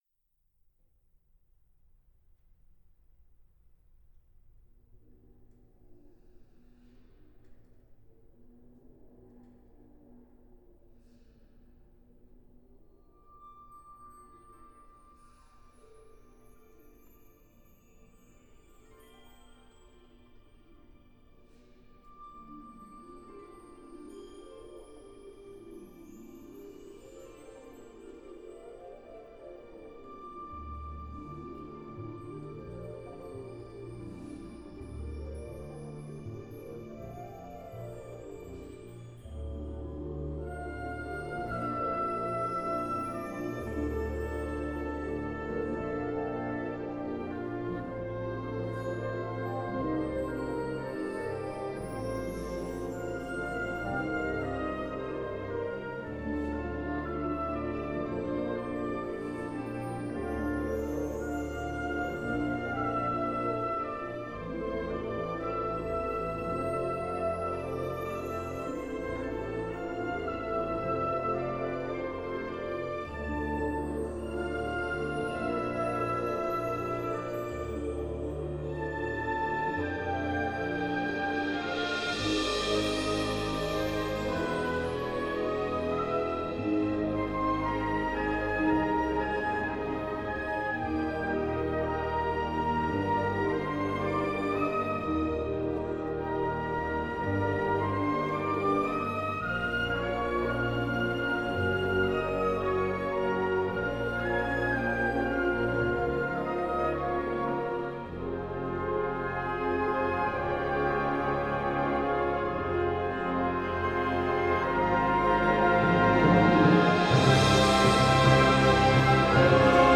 Kategorie Blasorchester/HaFaBra
Unterkategorie Suite